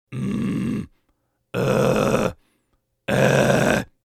Le false cord scream
False cord scream - phonation soufflée
07Grunt-complexe-False-Cord-Scream-phonation-soufflee.mp3